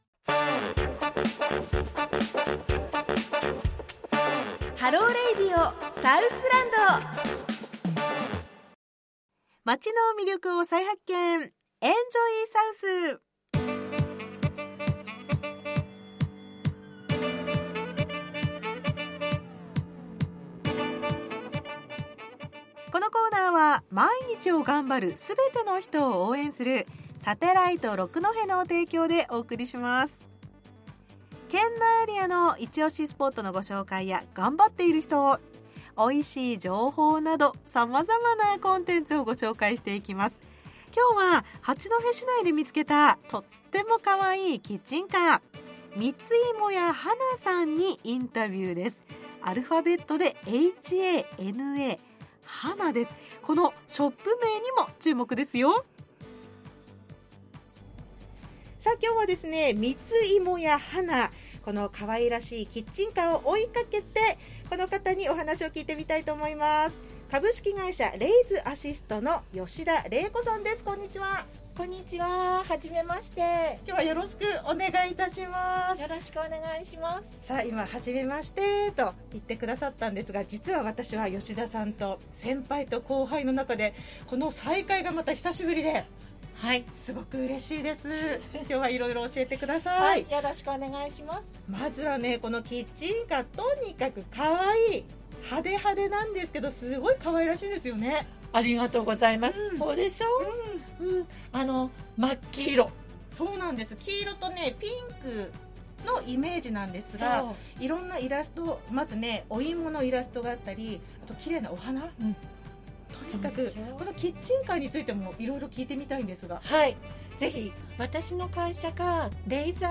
ラジオ
2021年度の放送